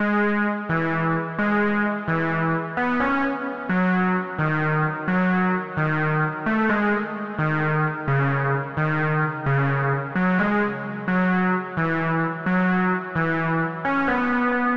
描述：它是D小调，12拍蓝调Shema再次。
标签： 灵魂 蓝调 小提琴 贝斯 钢琴 打击乐器
声道立体声